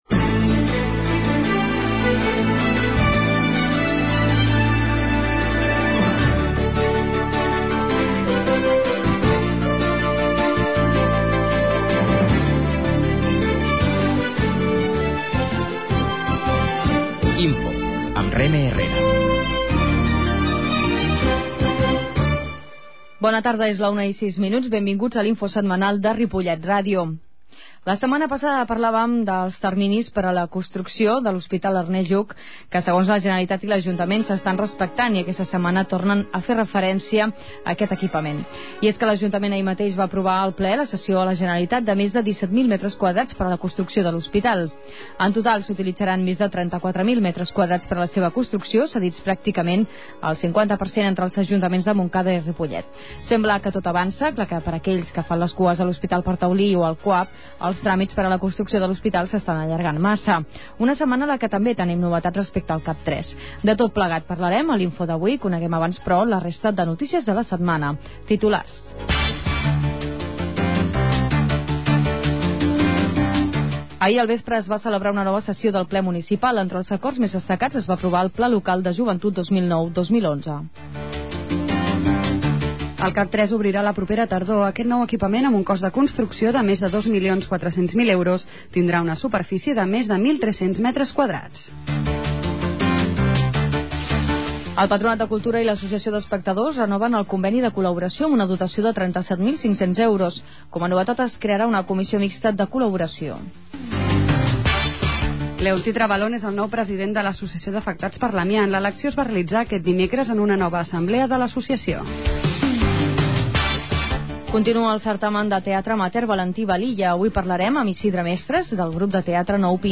La qualitat de so ha estat redu�da per tal d'agilitzar la seva desc�rrega.